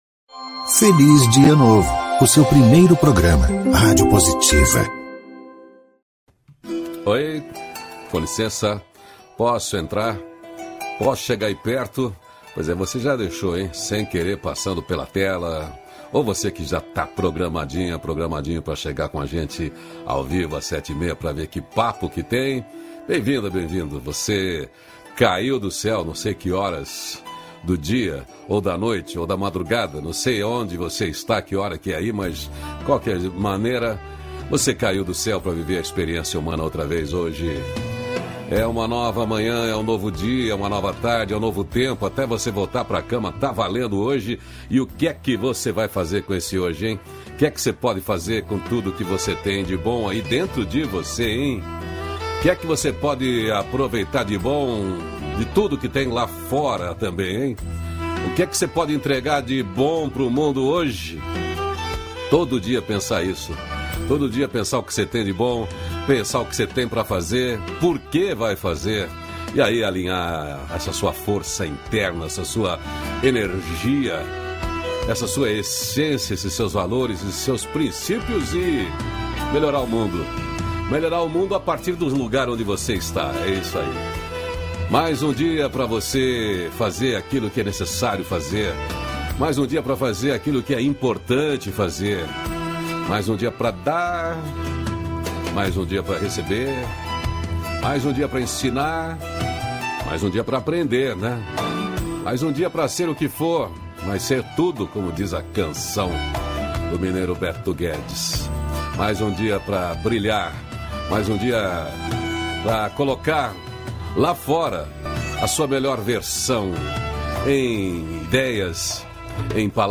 -512FelizDiaNovo-Entrevista.mp3